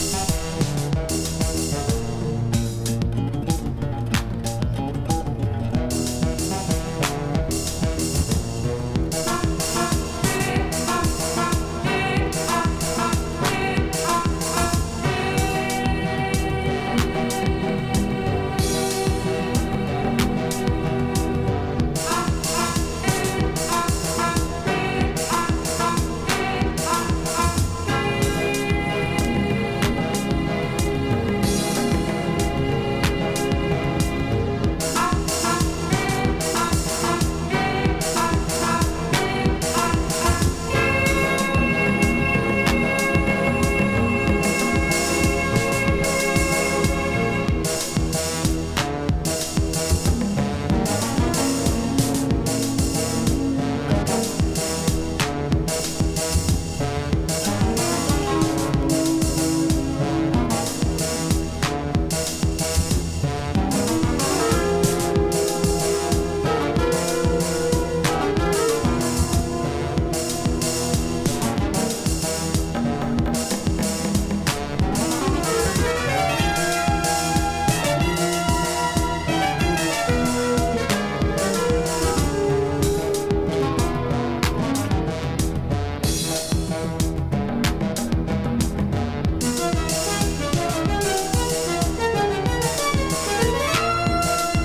アンビエントムード
ドラムマシン使用のエクスペリメンタル・ポップ